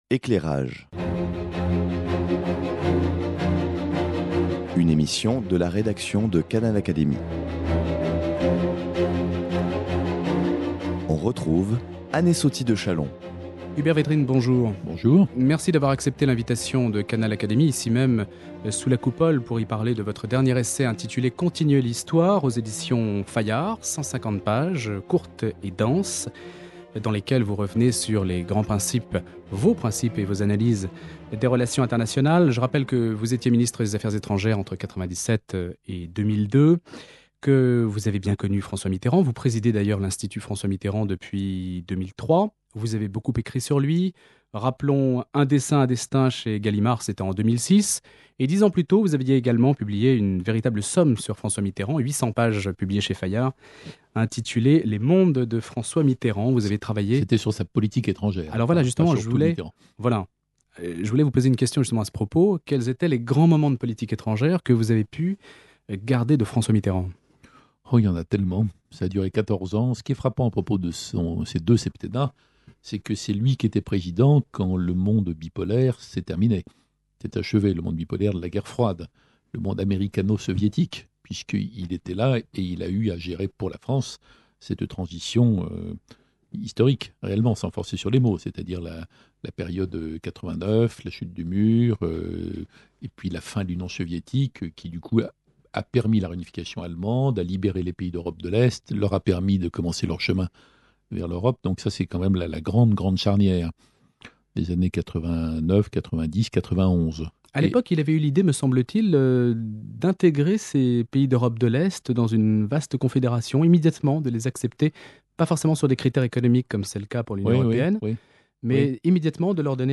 Dans cet entretien, Hubert Védrine prend le temps d'expliquer des situations complexes, qu’il s’agisse du blocage des institutions européennes ou de la crise au Proche-Orient.